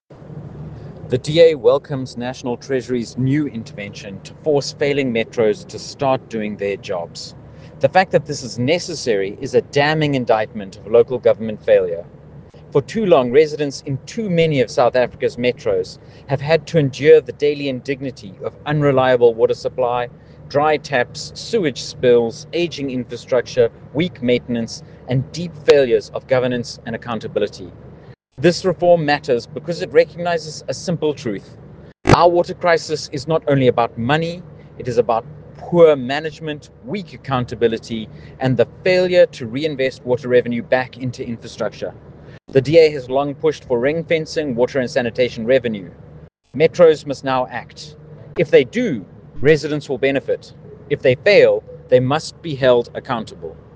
soundbite by Stephen Moore MP.